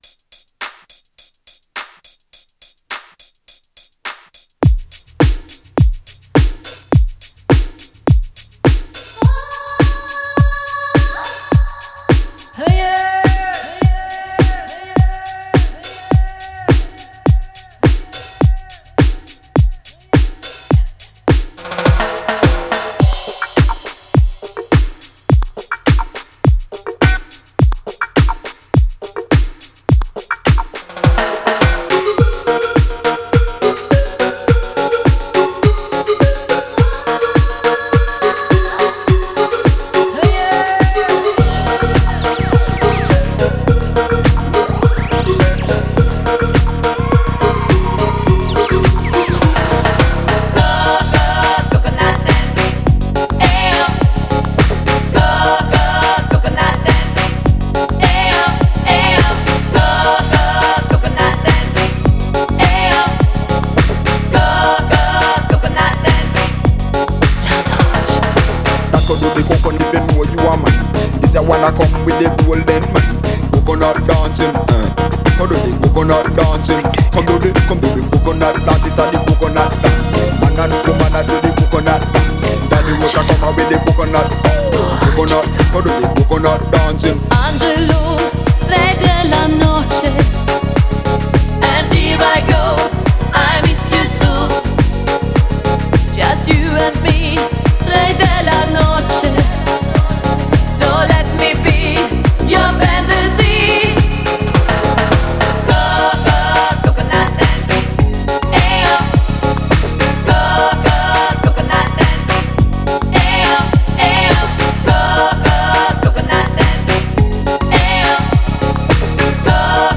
Genre: Euro-House.